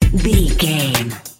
Aeolian/Minor
synthesiser
drum machine
hip hop
Funk
neo soul
acid jazz
energetic
bouncy
Triumphant
funky